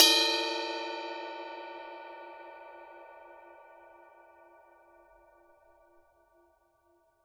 susCymb1-hit-bell_mf.wav